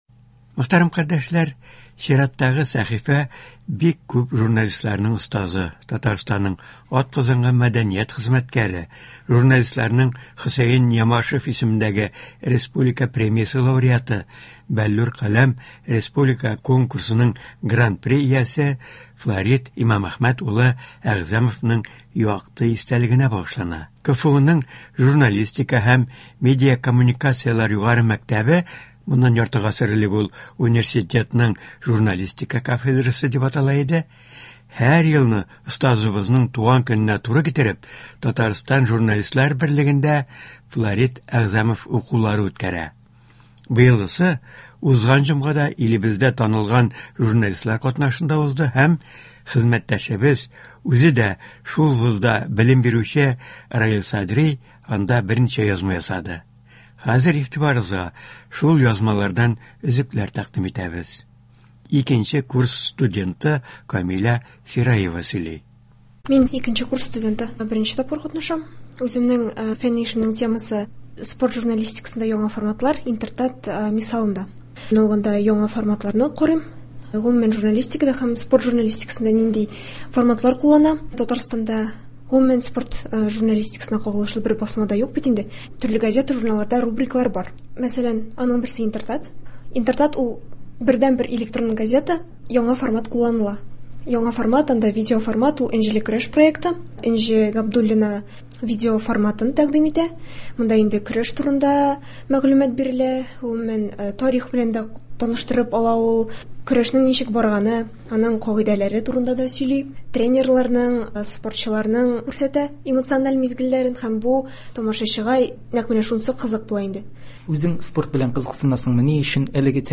Туры эфир (19.02.24)